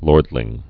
(lôrdlĭng)